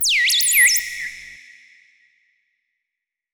Theremin_FX_02.wav